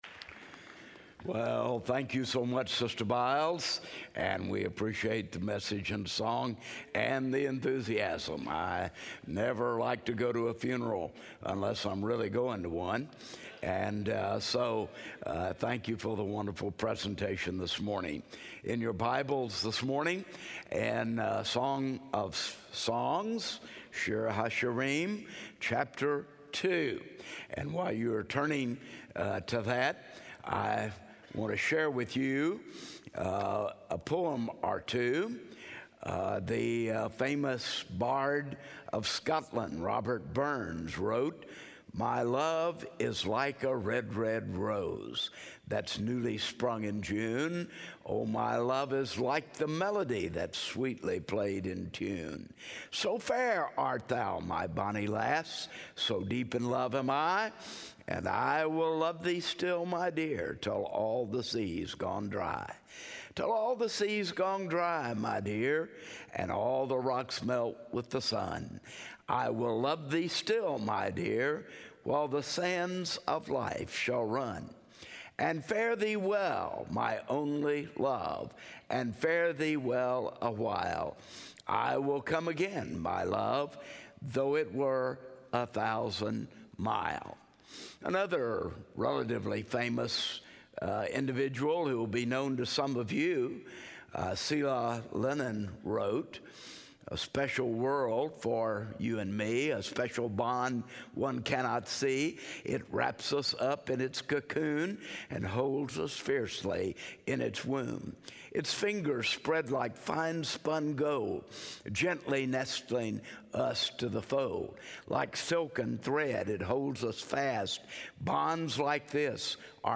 speaking on Song of Songs 2:2-17 in SWBTS Chapel
Sermons